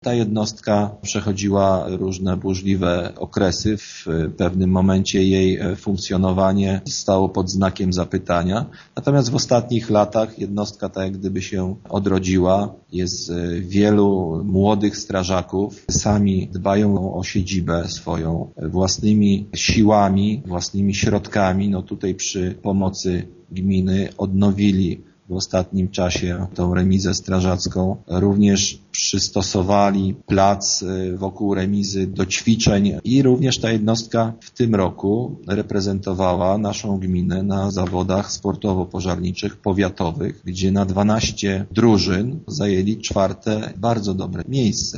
W gminie Kazimierz jest 7 jednostek OSP. Ta w Witoszynie jest jedną z najmłodszych – informuje burmistrz Grzegorz Dunia: